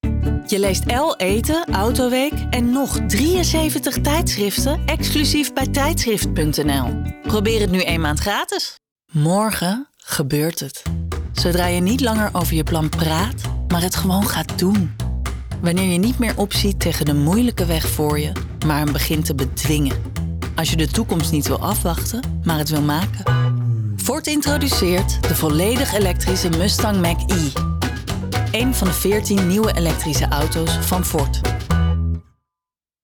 Voice Demo